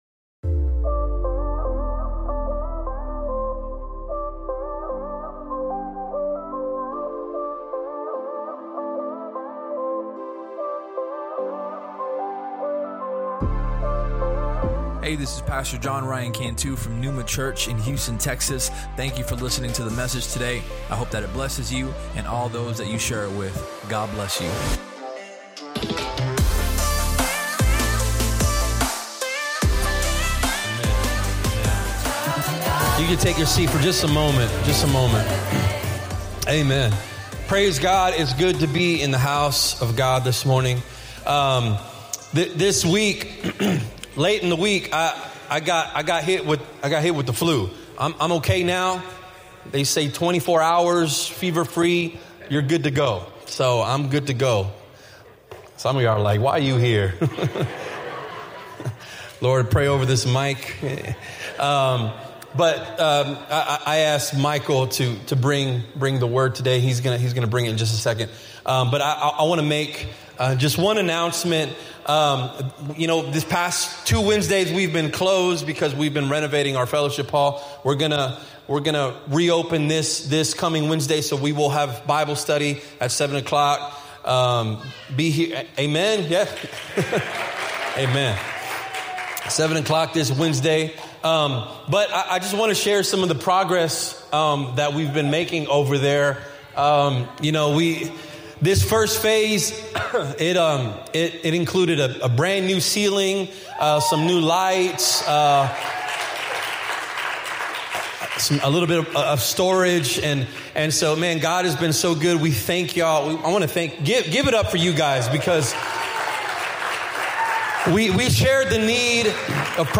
Welcome to PNEUMA Church's weekly podcast from Houston, TX.